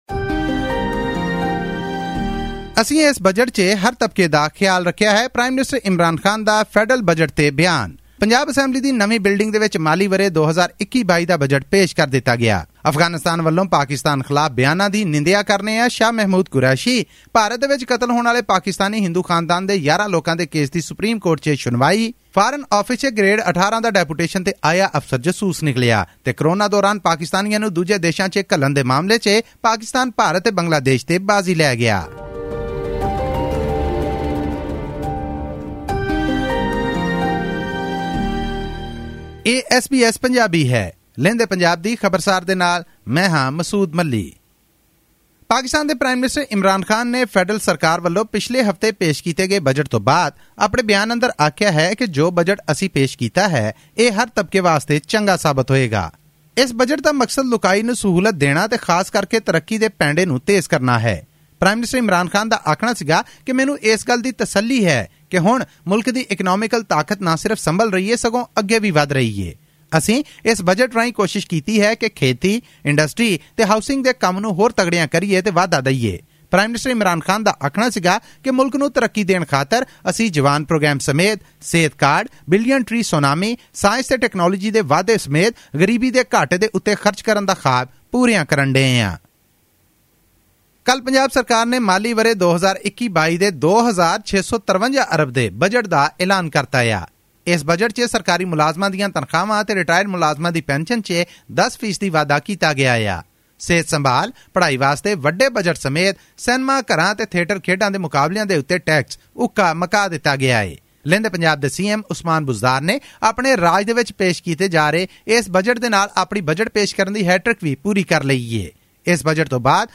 With a focus on economic stimulus in the backdrop of COVID-19, the Punjab government has approved the proposed budget of Rs 2.653 trillion for the fiscal year 2021-22. All this and more in our news bulletin from Pakistan.